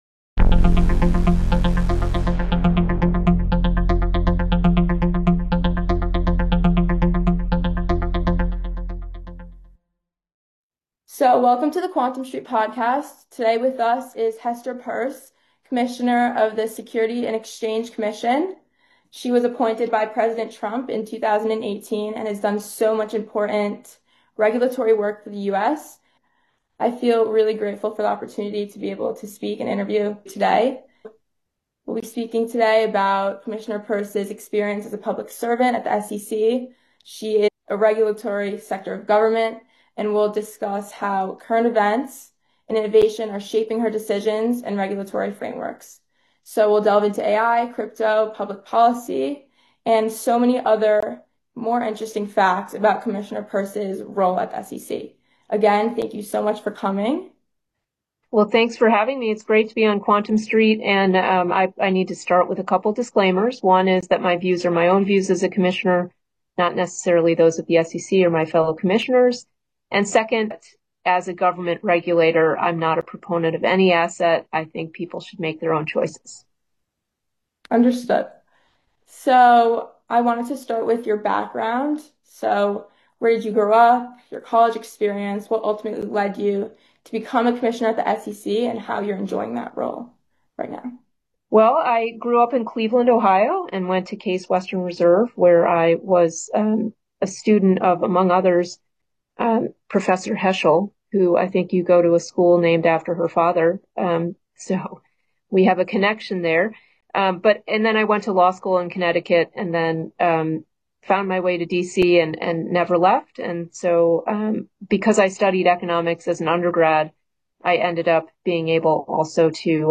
A Conversation With SEC Commissioner Hester Peirce
Quantum Street is back we sit down with current SEC Commissioner Hester Peirce. We discuss the current state of regulatory enforcement at the SEC and her views on regulation in the context of technological innovation.